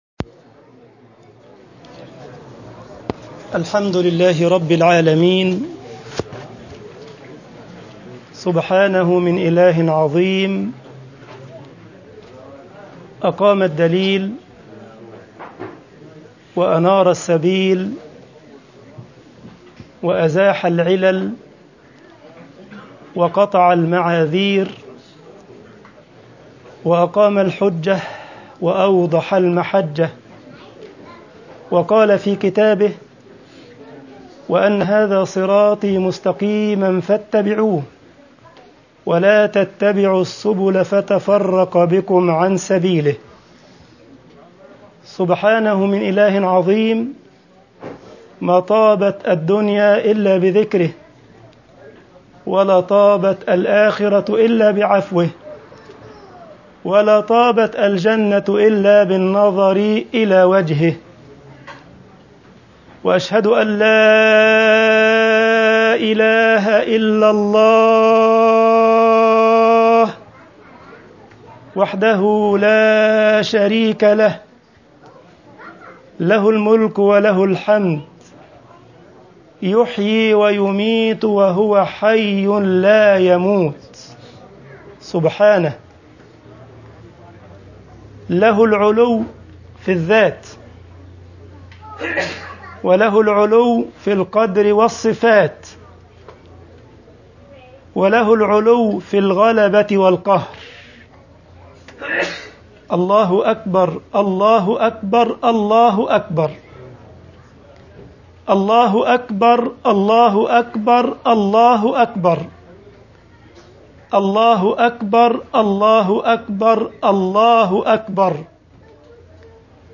الإستسلام التام لأمر الله سبيل النجاة - خطبة عيد الأضحى 2017 طباعة البريد الإلكتروني التفاصيل كتب بواسطة: admin المجموعة: مواضيع مختلفة Download التفاصيل نشر بتاريخ: الأحد، 10 أيلول/سبتمبر 2017 13:25 الزيارات: 1808 السابق التالي